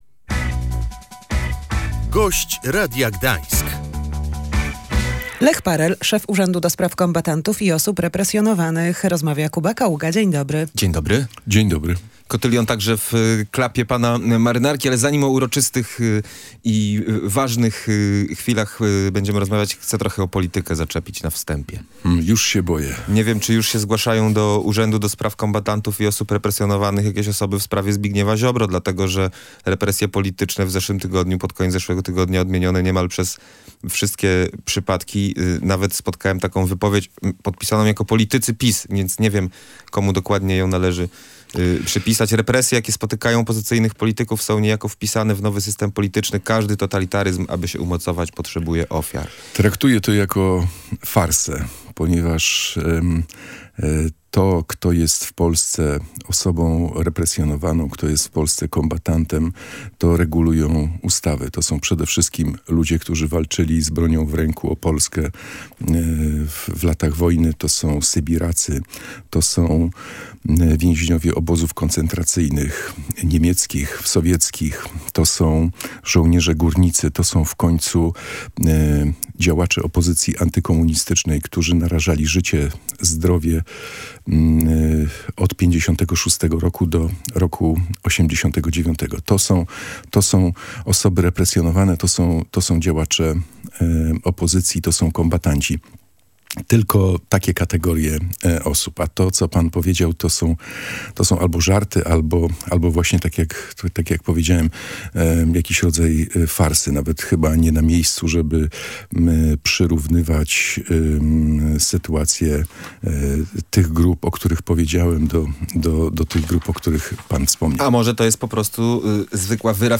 – W Gdańsku radosne świętowanie zaczęło się w 2003 roku – przypomniał w Radiu Gdańsk Lech Parell, szef Urzędu do Spraw Kombatantów i Osób Represjonowany